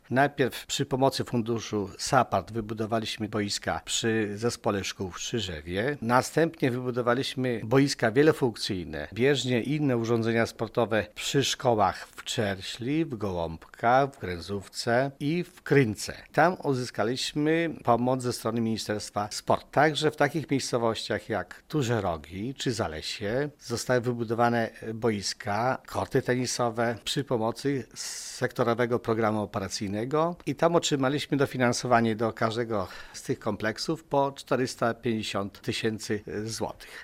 Gmina systematycznie pozyskiwała środki na ten cel z Unii Europejskiej oraz budżetu państwa – mówi Wiktor Osik: